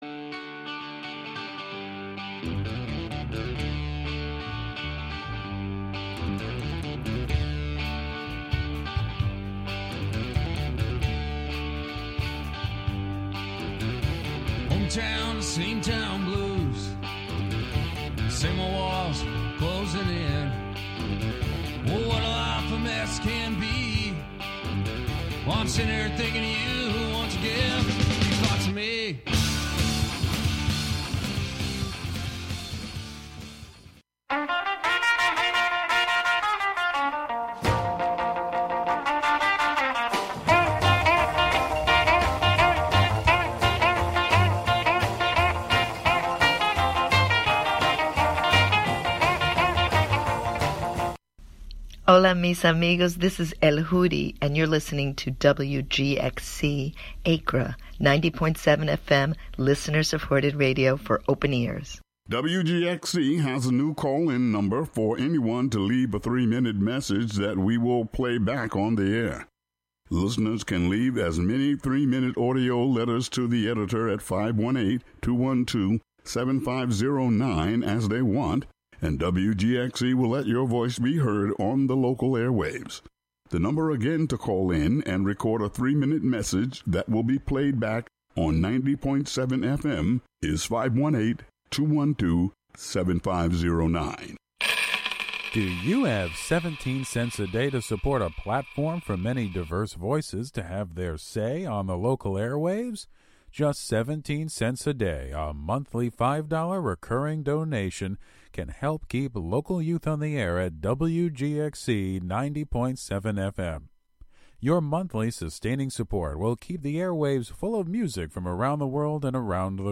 The Hammond B-3, the Wurlitzer, the Clavinet, the TONTO synthesizer — all featured in our playlist because we couldn't get enough organ music into the last show!
The show features music, field recordings, performances, and interviews, primarily with people in and around the Catskill Mountains of New York live from WGXC's Acra studio.